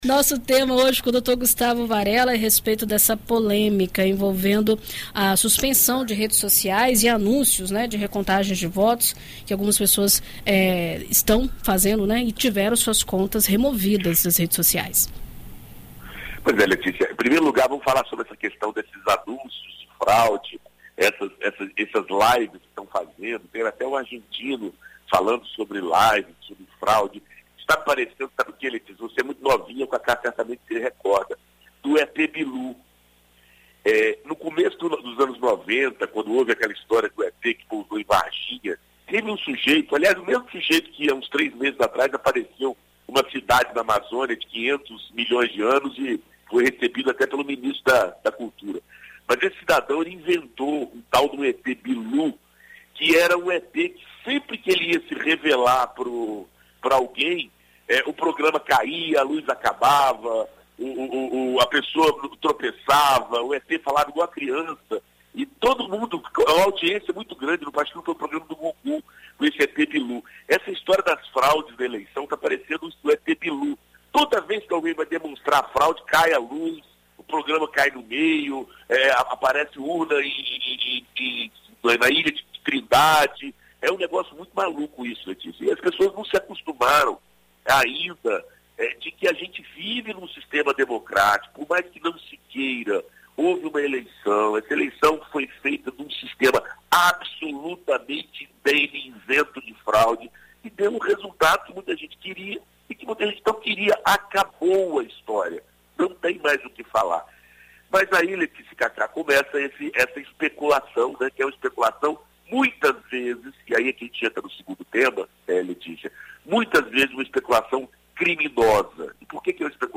Na coluna Direito para Todos desta segunda-feira (07), na BandNews FM Espírito Santo